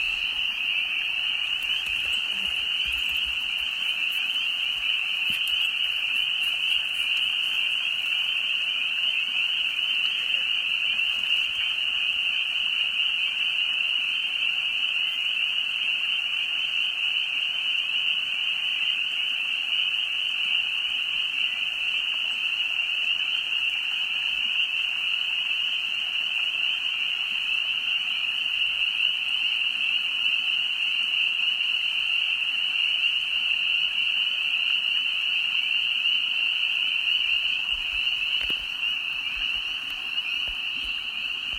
The climb down seemed to take no time at all, and along the way we passed a few cool beaver ponds with frogs singing their cacophonous anthem all around us.